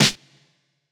Medicated Snare 14.wav